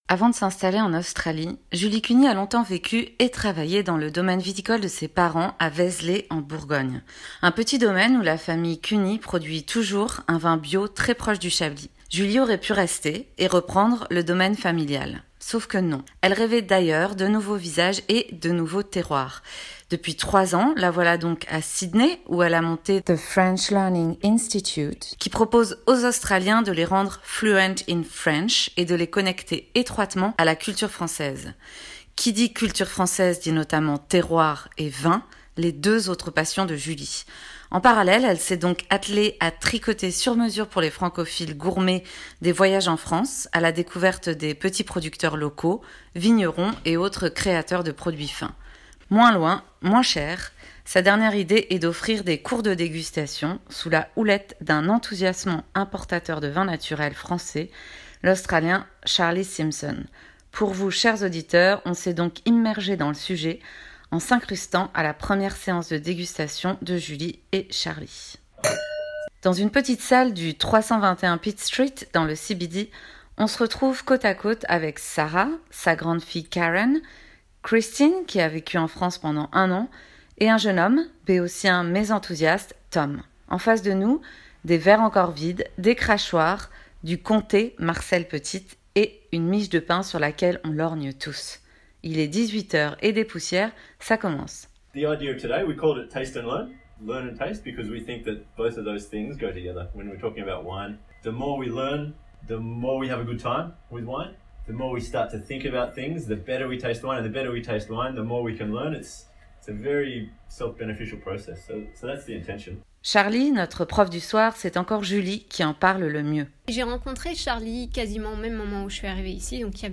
De l'art de goûter le vin (français) : reportage dans un cours de dégustation à Sydney